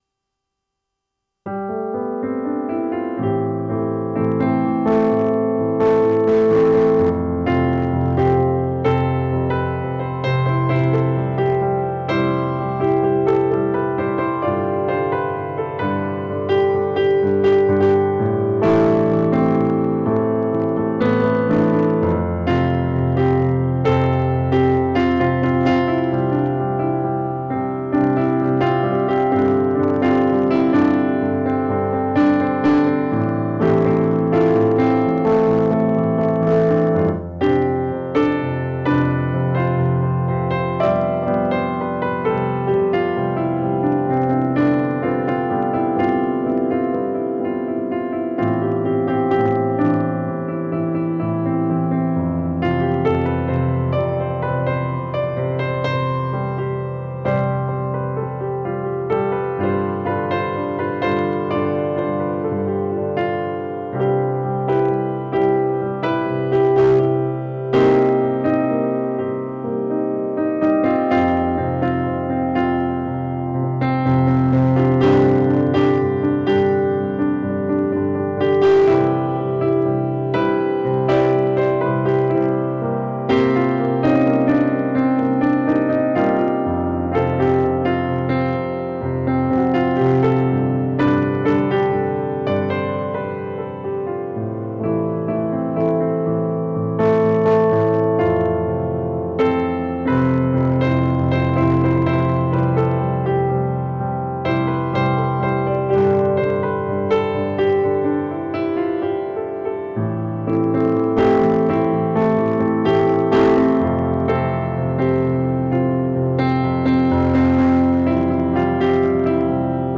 Instrumental only.
Such a lovely melody.
This is soft and beautiful.
Somewhat pensive and reflective.
Gorgeous piano piece.